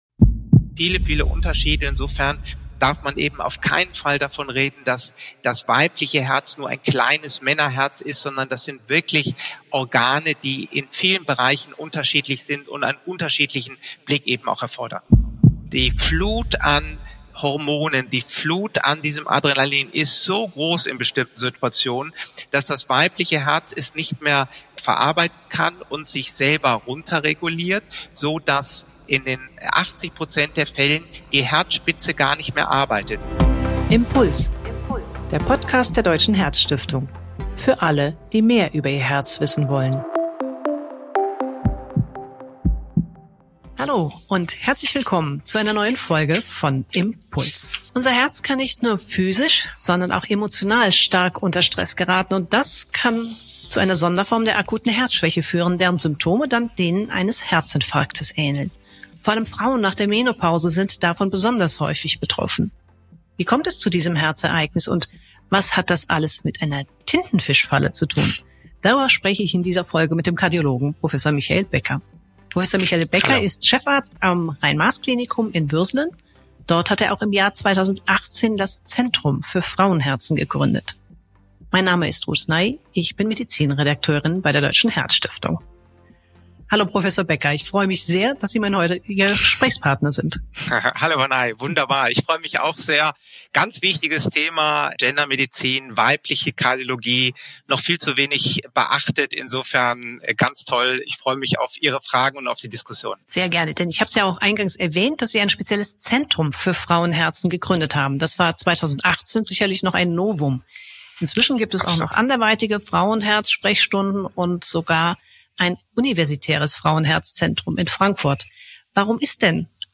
Wir sprechen mit Herzspezialisten und Herzpatienten locker und lehrreich über d a s zentrale Organ – unser Herz: Denn dessen Gesundheit ist uns bei der Deutschen Herzstiftung eine echte Herzensangelegenheit.